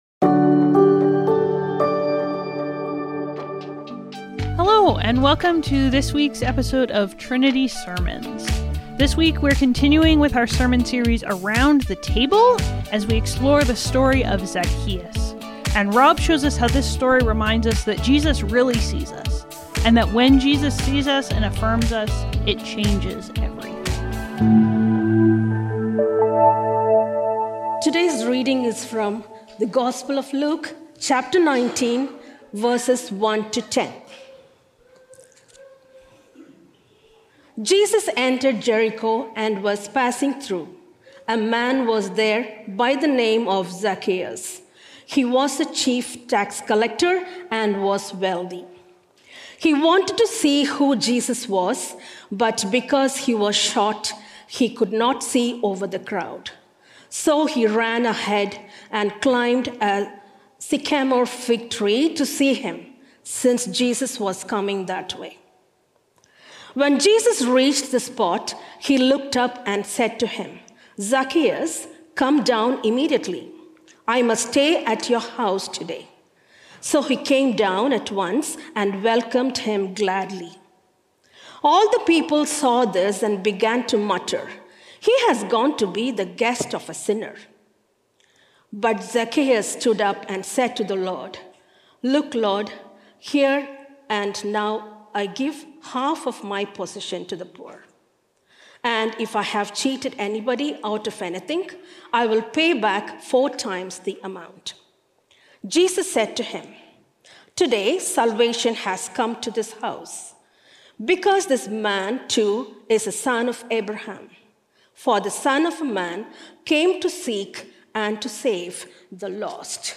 Trinity Streetsville - Bad Company | Around The Table | Trinity Sermons